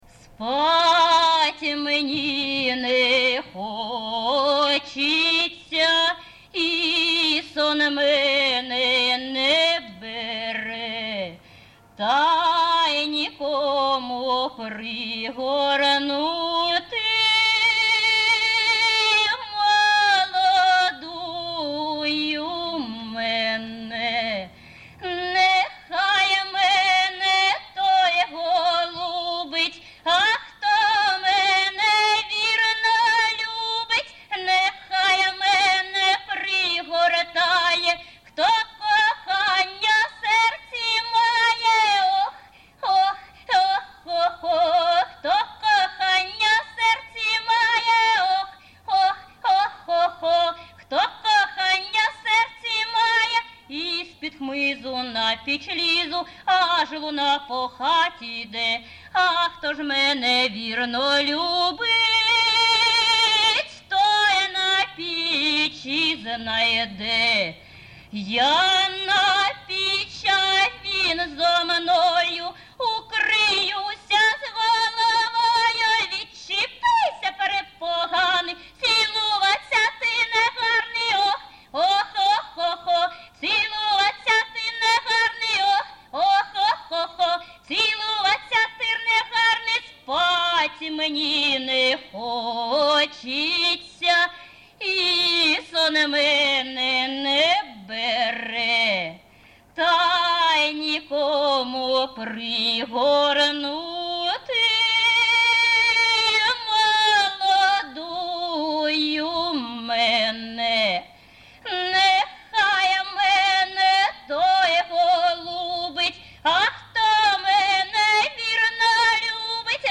Виконавиця співає не в традиційній, а в сценічній манері